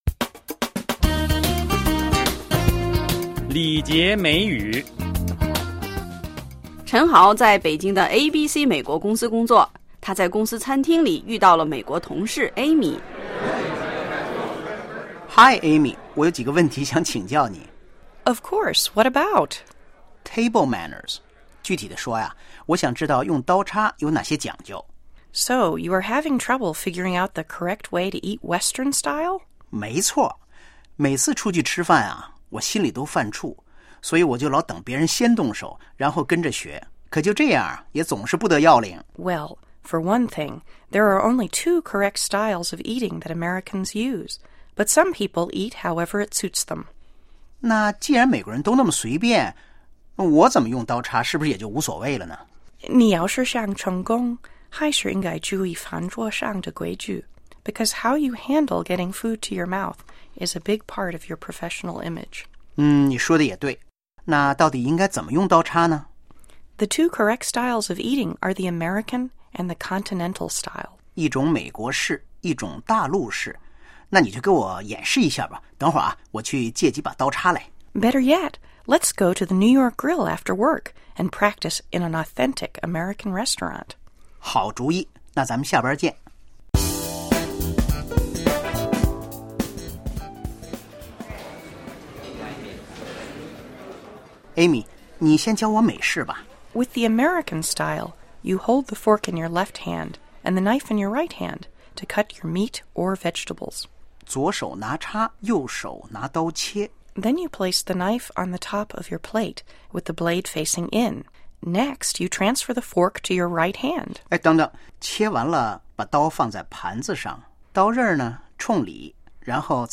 (Office ambience)